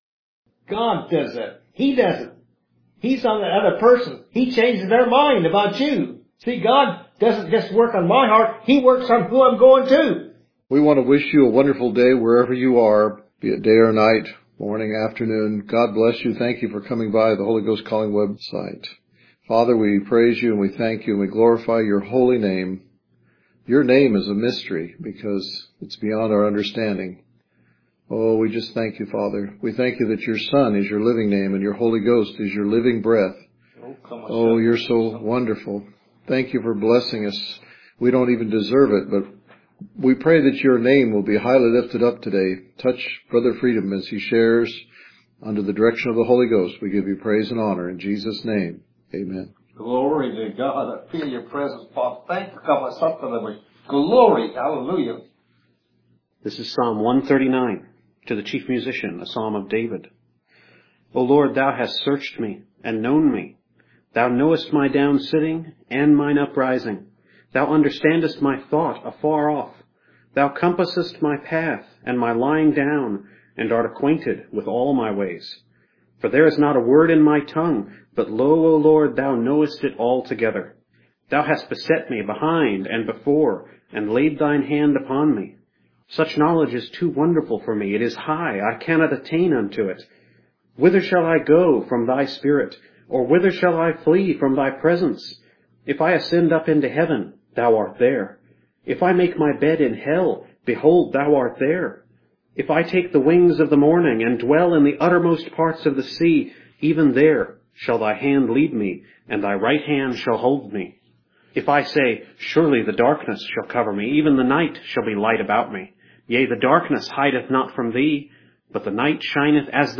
Scripture Reading: Psalms 139:1-24; Ezekiel 22:28-31; Jeremiah 29:11-13